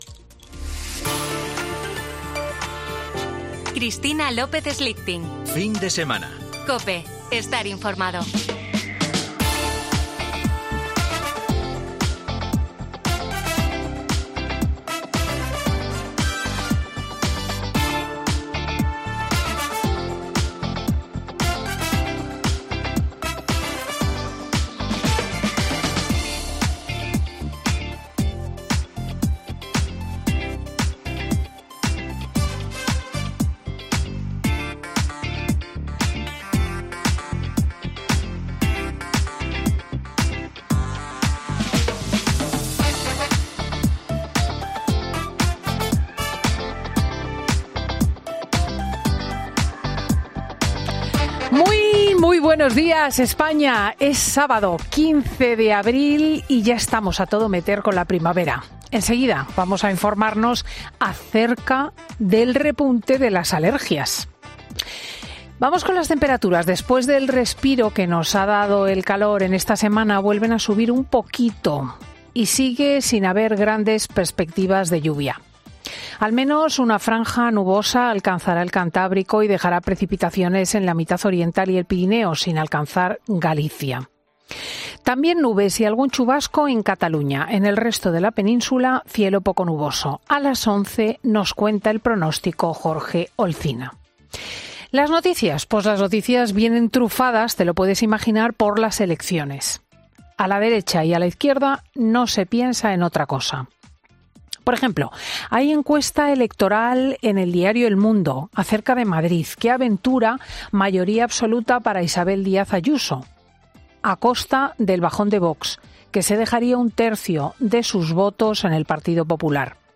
AUDIO: Las encuentras preelectorales, Doñana o la ley de vivienda, en el monólogo de Cristina López Schlichting